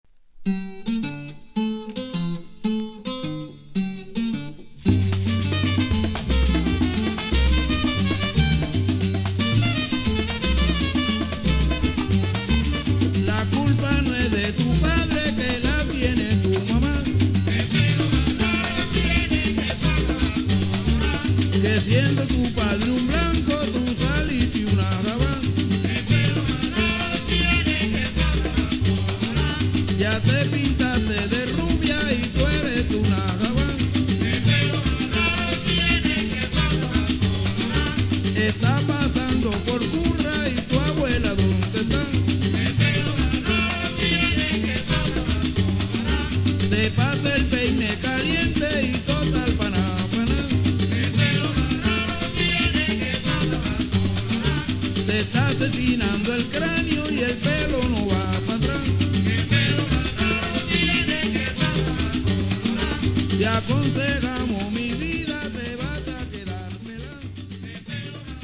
80S Cuban Son